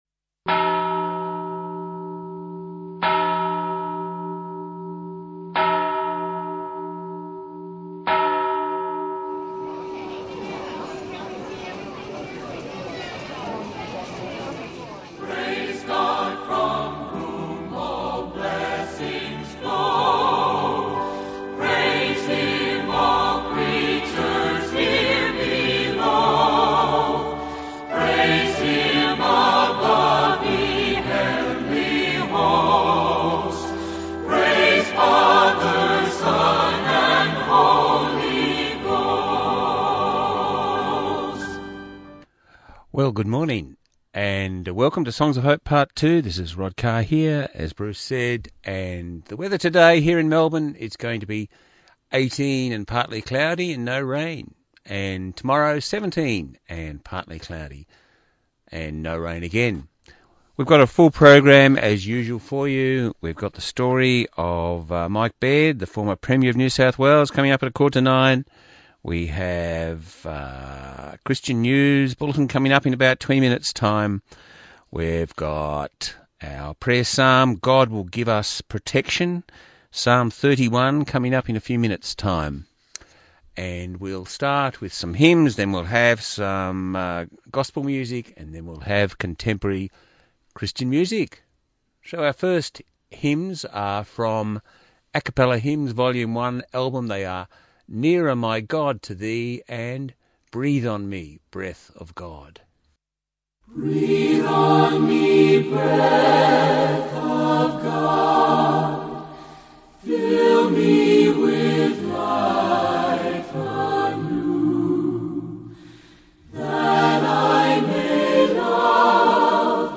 5Nov17 1hr Christian music
Listen again to Songs of Hope part 2 broadcast on 5Nov17 on Southern FM 88.3